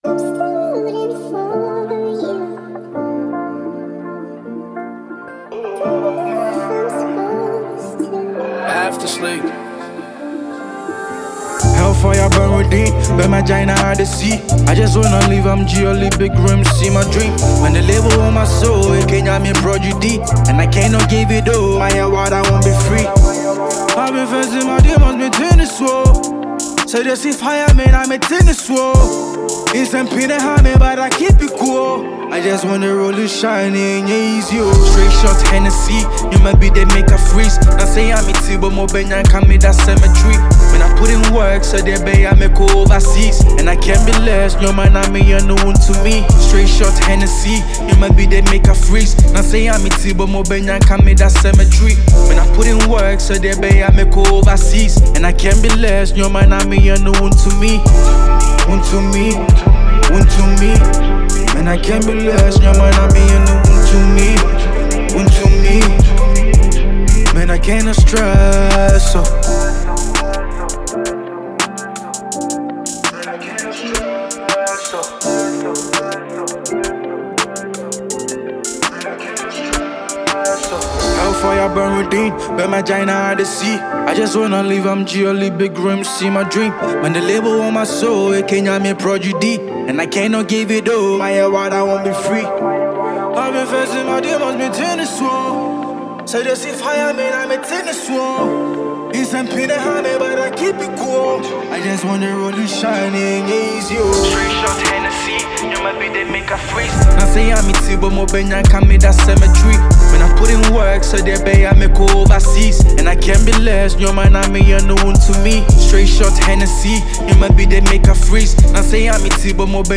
Ghana Music
is a raw, unapologetic Drill record